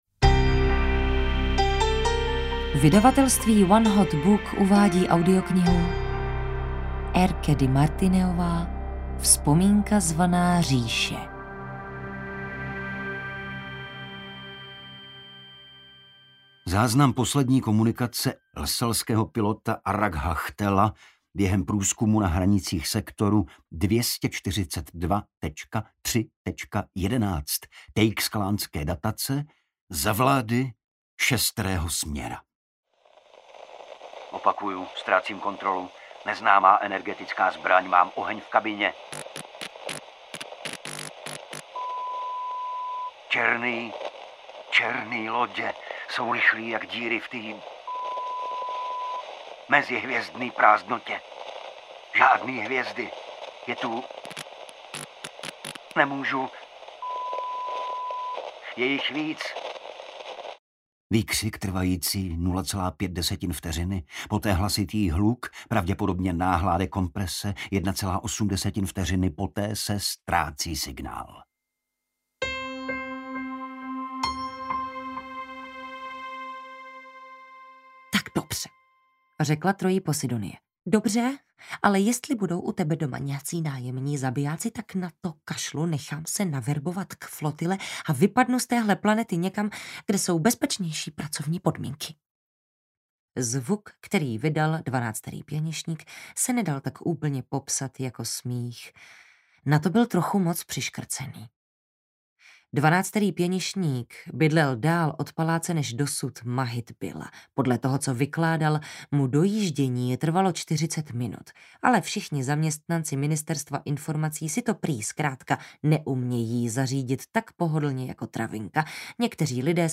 Vzpomínka zvaná říše audiokniha
Ukázka z knihy
• InterpretTereza Dočkalová, Igor Bareš